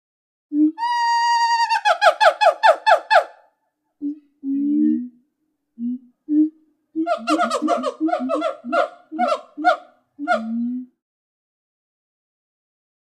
Gibbon Call. One Short Hoot Followed By A Series Of High Pitched Yelps Ending With Another Series Of Hoots And Yelps. Close Perspective.